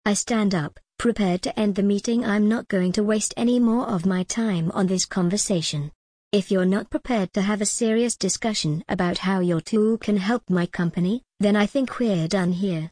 realistic_female_voice.mp3